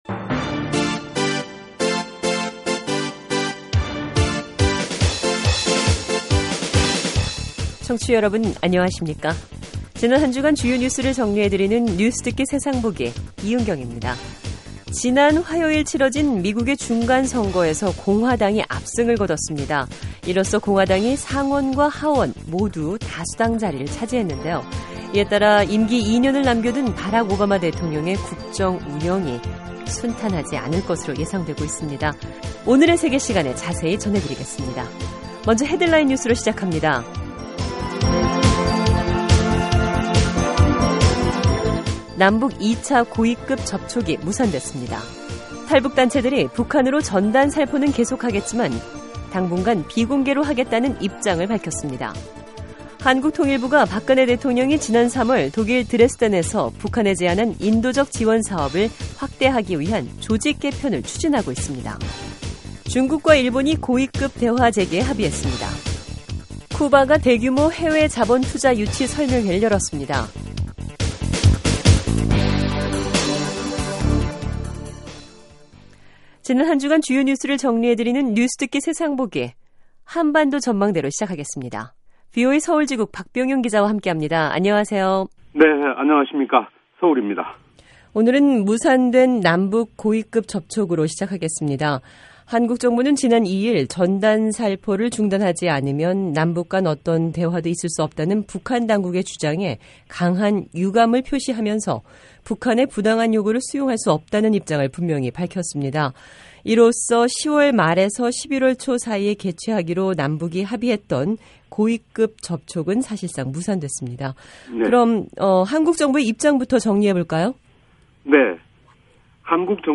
지난 한주간 주요 뉴스를 정리해 드리는 뉴스듣기 세상보기 입니다. 남북 2차 고위급 접촉이 결국 무산됐습니다. 미국 중간선거에서 공화당이 압승을 거두고 상원에서도 다수당이 됐습니다. 중국과 일본이 관계 개선을 위해 고위급 대화를 재개하기로 했습니다.